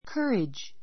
courage kə́ːridʒ